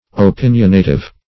Opinionative \O*pin"ion*a*tive\, a.